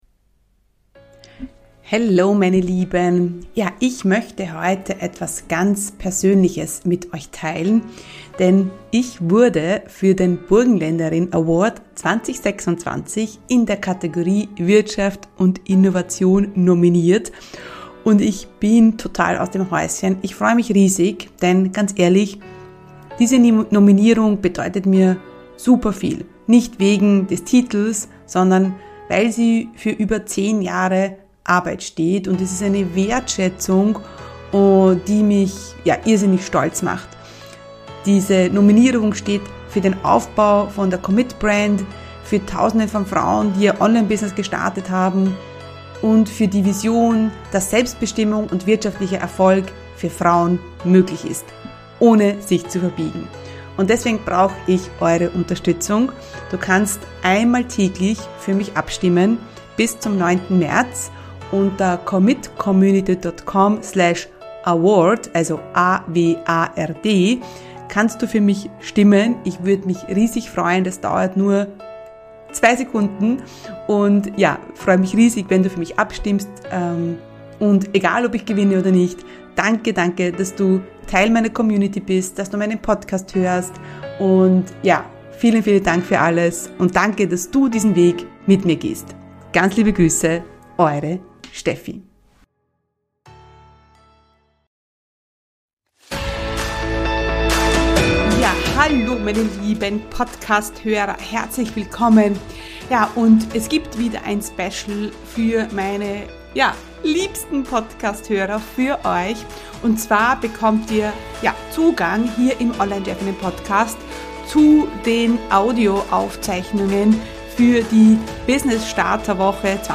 344 Online Business Workshop Woche: 90 Tage zum ersten Kunden (Aufzeichnung Tag 3) ~ Online Chefinnen Podcast